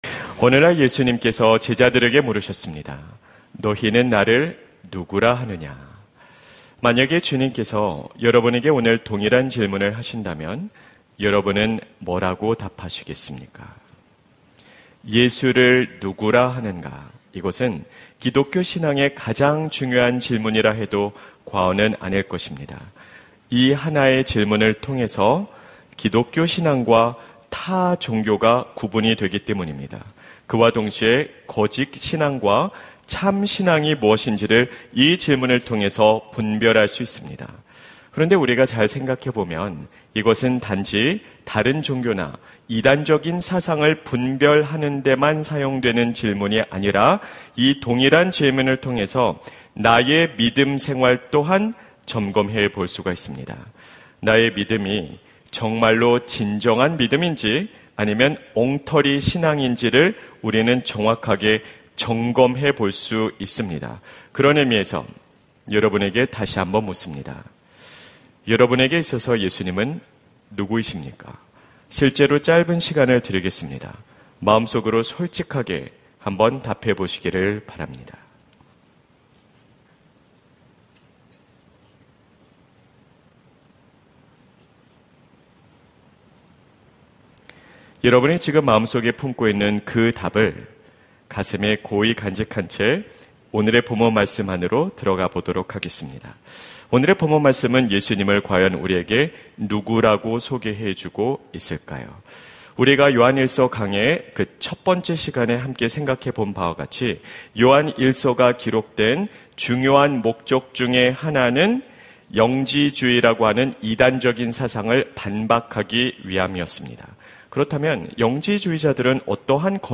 설교 : 수요향수예배 예수를 누구라 하는가? 설교본문 : 요한1서 2:18-23